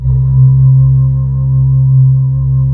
Index of /90_sSampleCDs/USB Soundscan vol.28 - Choir Acoustic & Synth [AKAI] 1CD/Partition D/12-WAVING